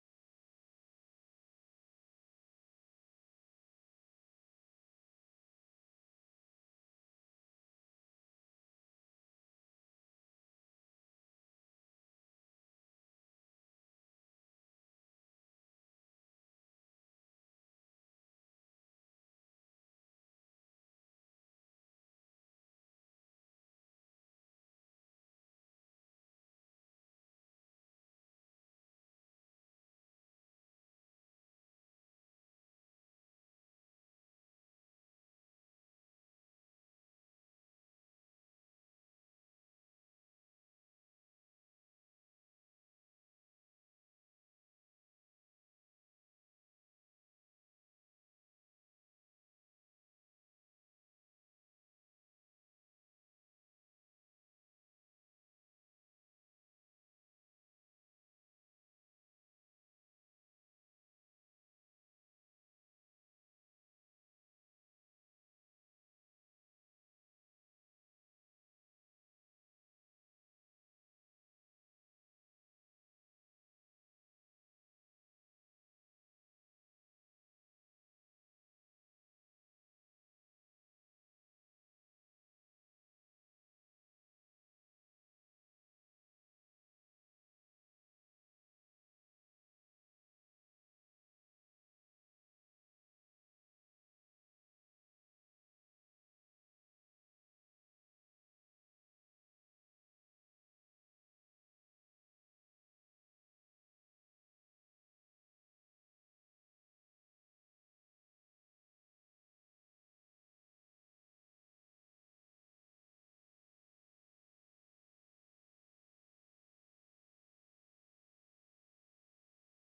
생방송 여기는 워싱턴입니다 저녁